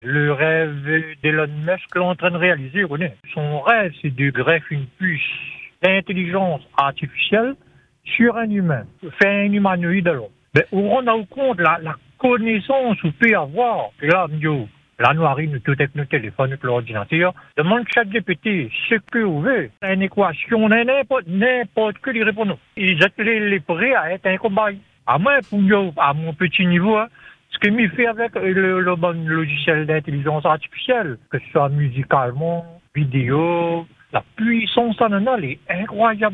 Podcast – « On touche au futur » : un auditeur fasciné par l’IA… et surtout par Neuralink